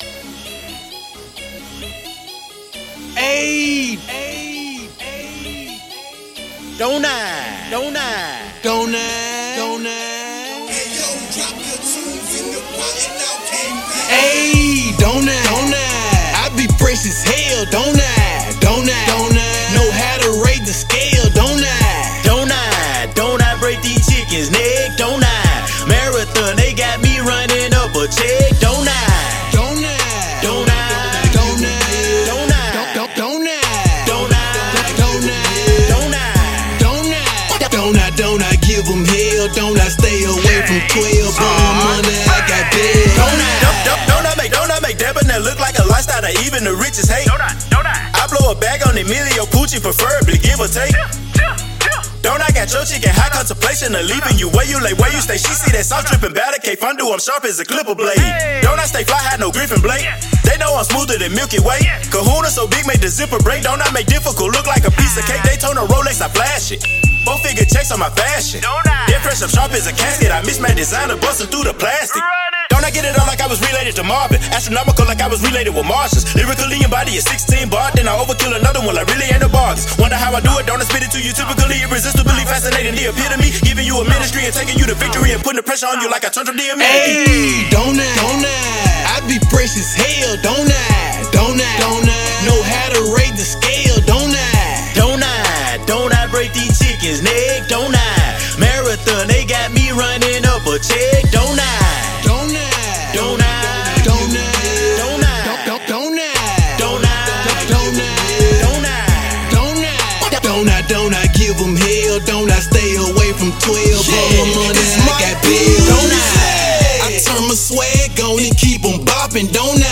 Hiphop
a hot rap duo group